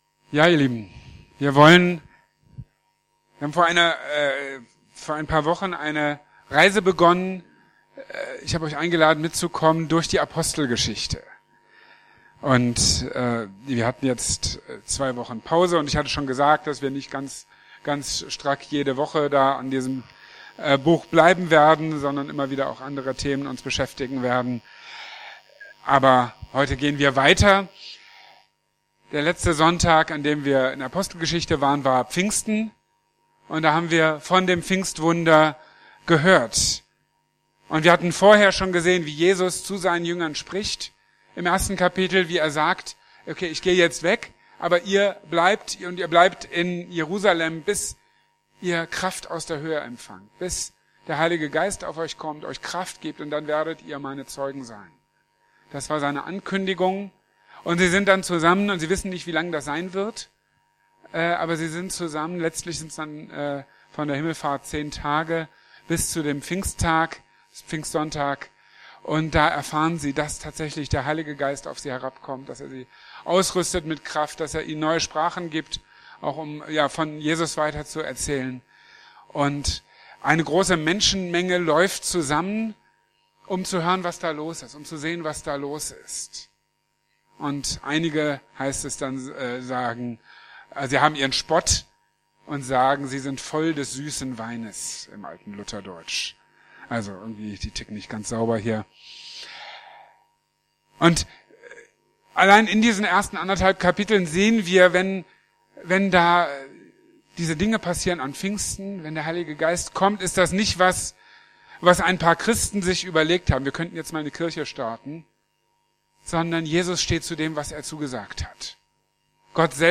Jesus - gekreuzigt, verherrlicht, bezeugt | Marburger Predigten